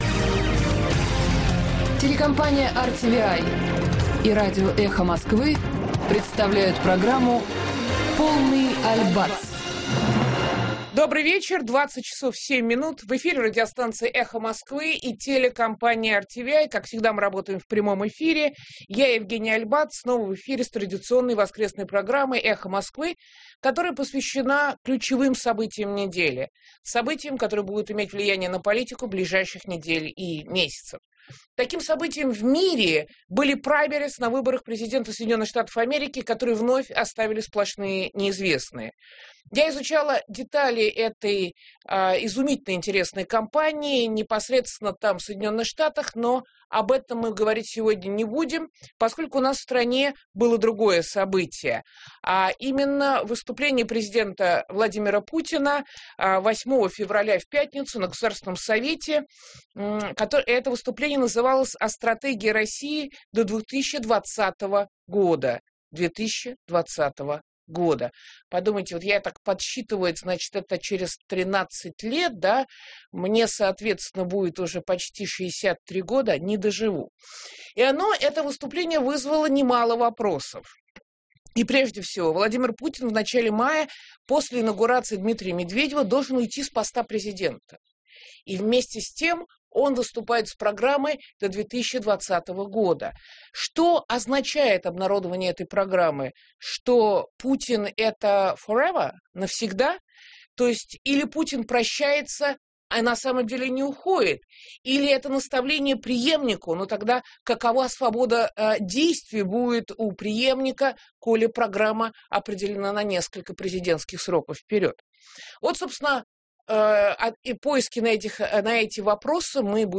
Е.АЛЬБАЦ: Константин Исаакович Сонин, профессор российской экономической школы.